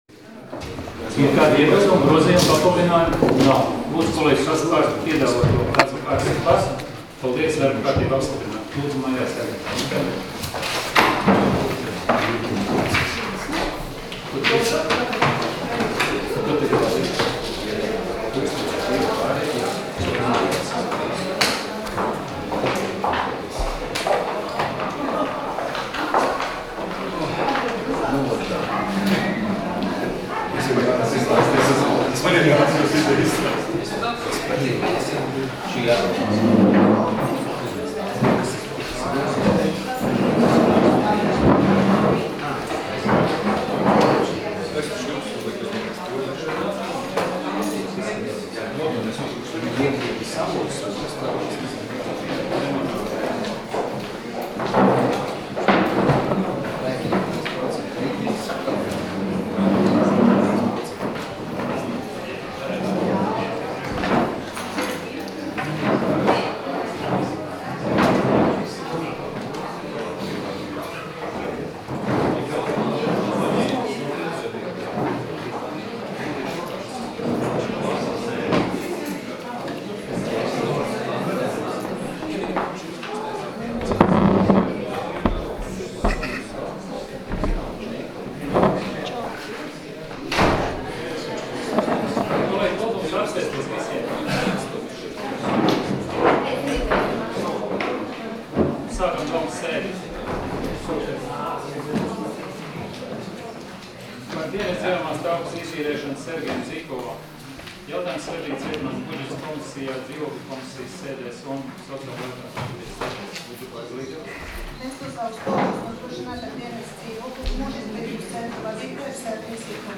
Domes sēdes 26.08.2016. audioieraksts